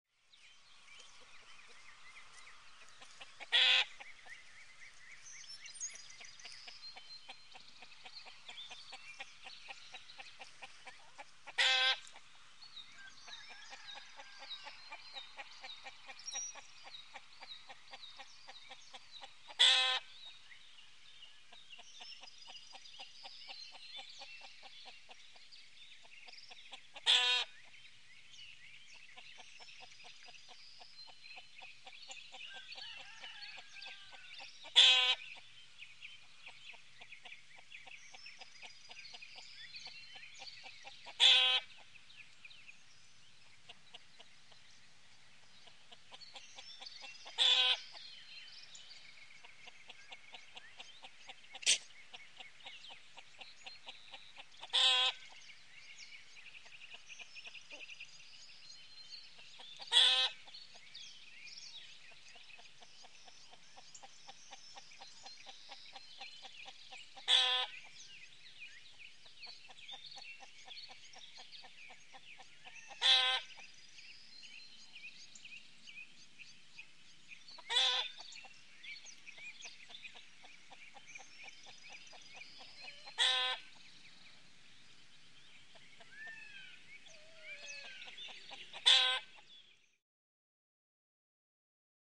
Country Ambience | Sneak On The Lot
Calm Chicken Clucking With Background Rooster, Light Breeze And Insects.